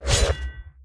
Melee Weapon Attack 23.wav